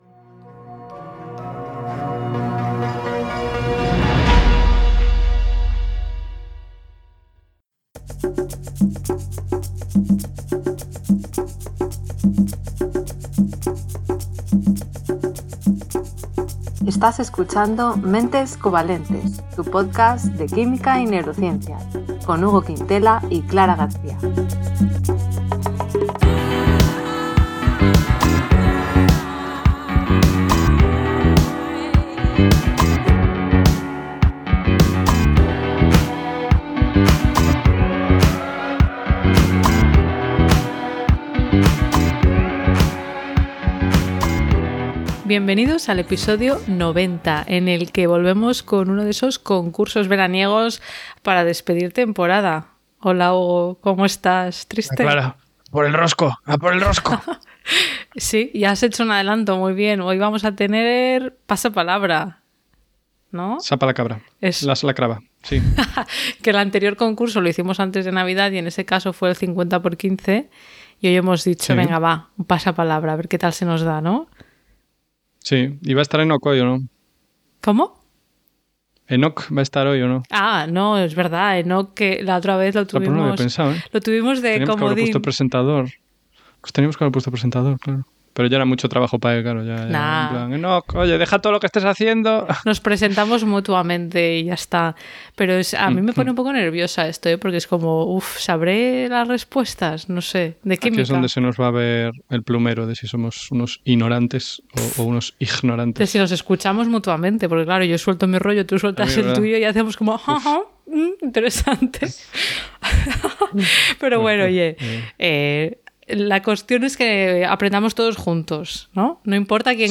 Reflexionamos sobre estas y más preguntas en una tertulia distendida..Puedes seguirnos en redes.